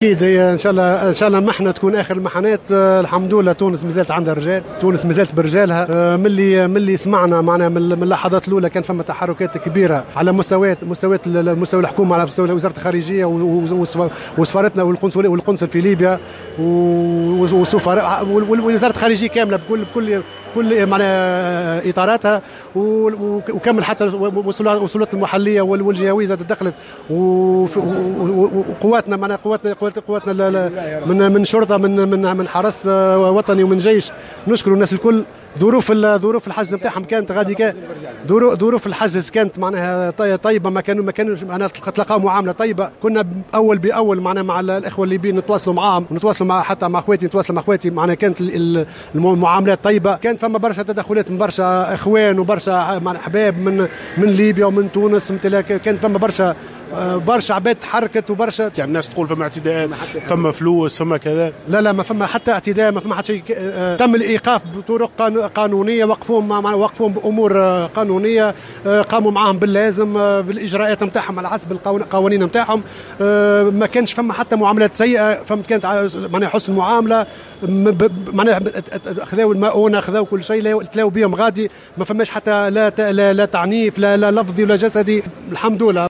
شقيق بحار